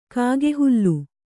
♪ kāge hullu